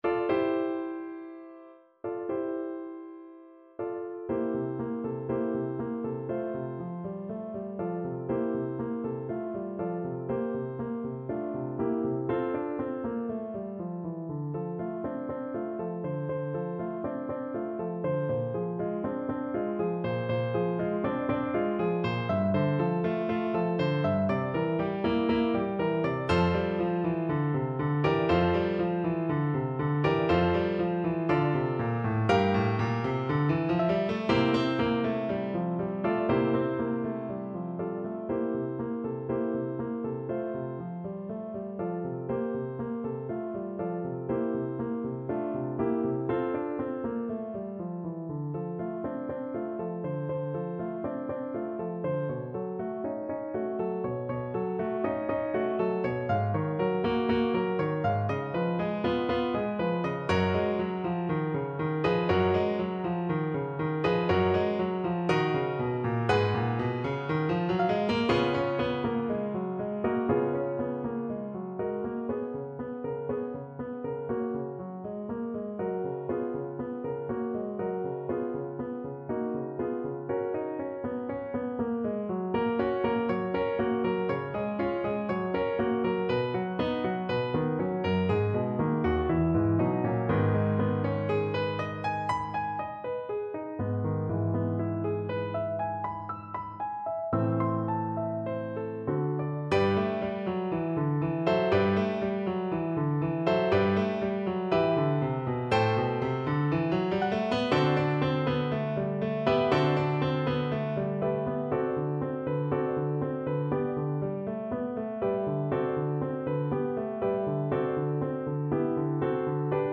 Play (or use space bar on your keyboard) Pause Music Playalong - Piano Accompaniment Playalong Band Accompaniment not yet available transpose reset tempo print settings full screen
French Horn
4/4 (View more 4/4 Music)
F major (Sounding Pitch) C major (French Horn in F) (View more F major Music for French Horn )
Con anima =120
Classical (View more Classical French Horn Music)